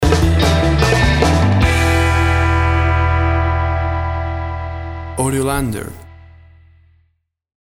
Tempo (BPM) 150